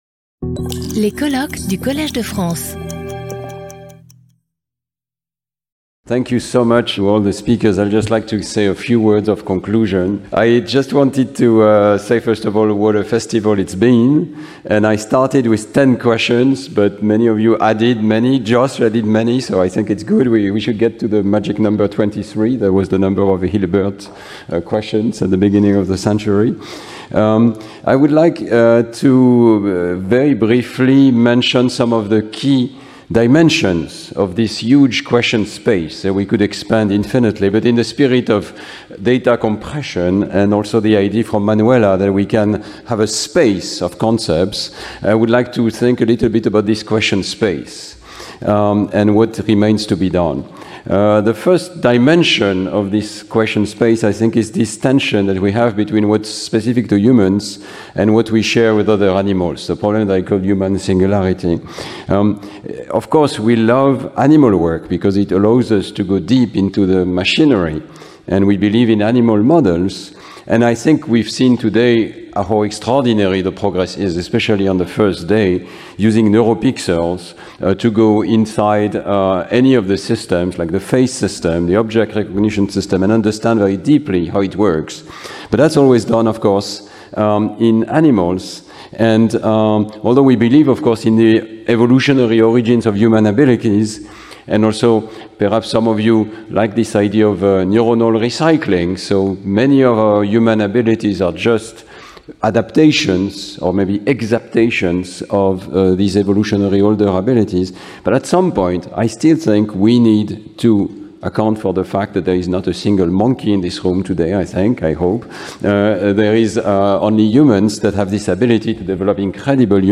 Intervenant(s) Stanislas Dehaene Professeur du Collège de France